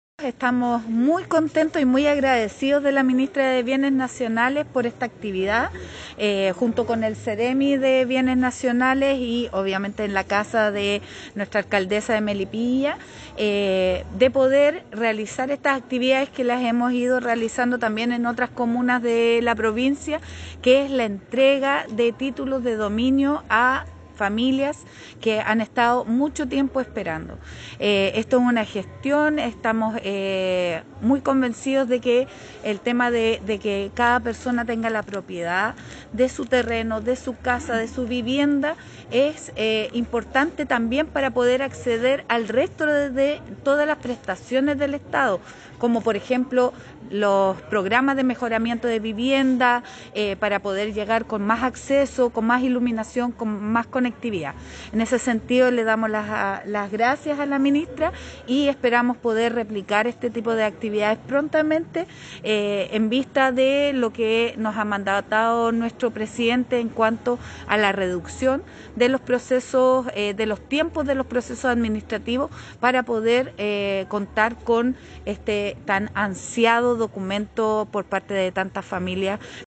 Tras una significativa ceremonia, más de 60 familias de la comuna de Melipilla recibieron sus ansiados Títulos de Dominio, en una actividad presidida por la Ministra de Bienes Nacionales, Javiera Toro; la Delegada Presidencial Provincial, Sandra Saavedra; el seremi de Bienes Nacionales, Germán Pino; y la alcaldesa Lorena Olavarría.